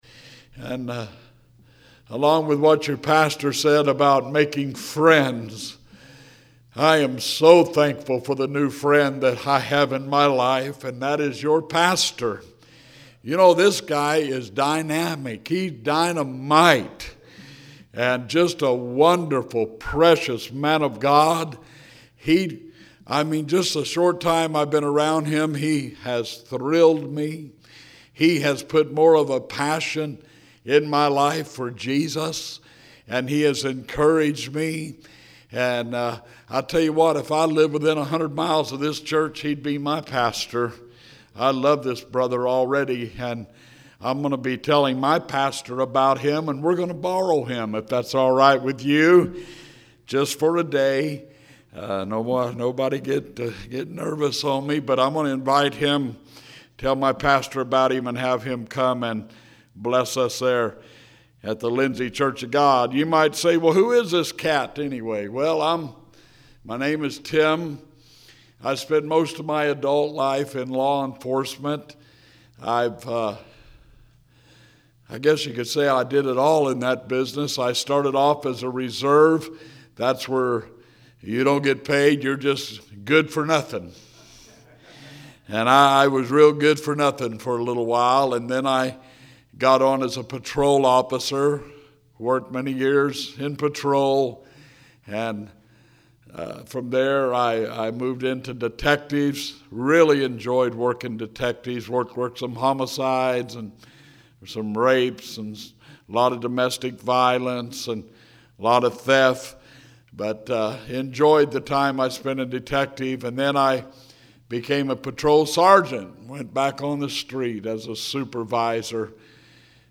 Evening Sermons